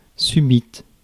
Ääntäminen
Ääntäminen France: IPA: /sy.bit/ Haettu sana löytyi näillä lähdekielillä: ranska Käännöksiä ei löytynyt valitulle kohdekielelle.